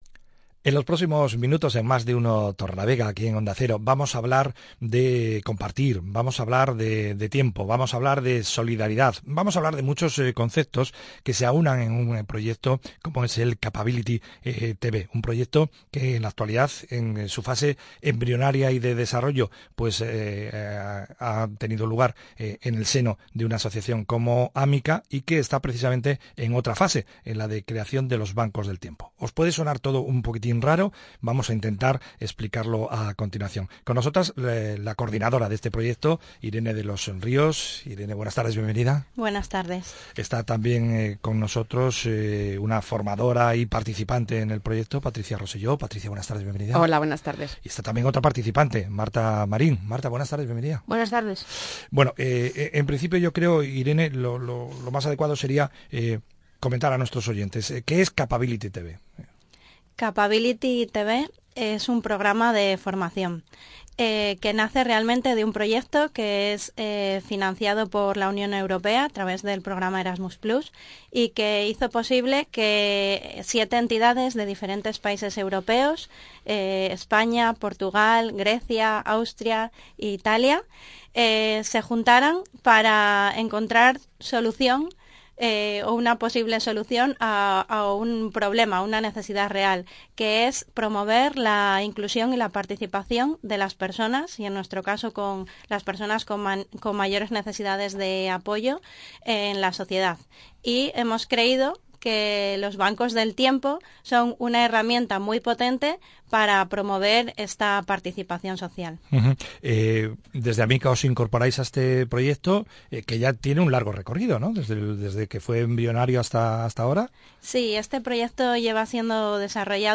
Entrevista Onda Cero Torrelavega: CAPABILITY-TB